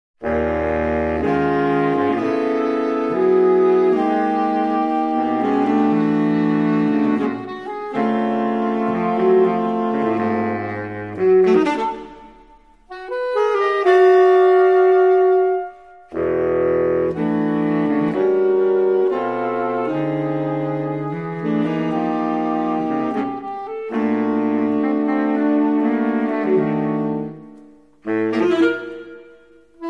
für Saxophonquartett
Neue Musik
Jazz
Ensemblemusik